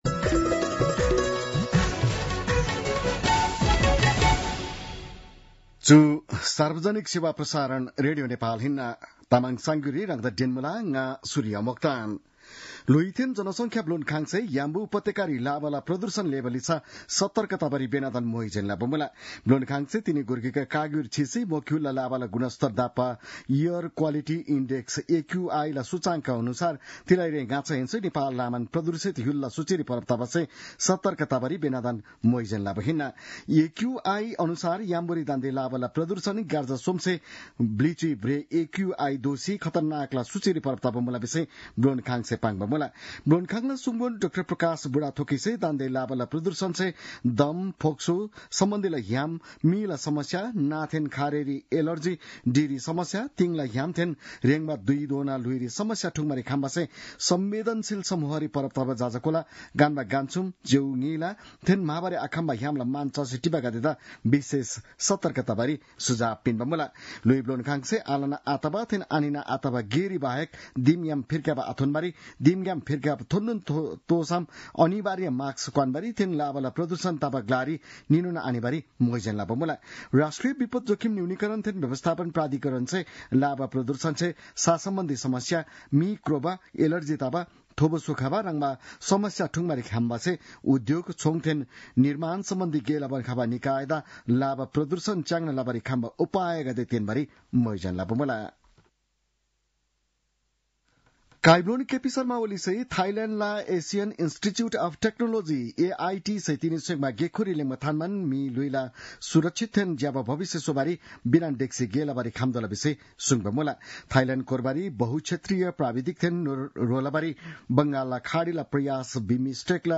तामाङ भाषाको समाचार : २१ चैत , २०८१